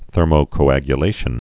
(thûrmō-kō-ăgyə-lāshən)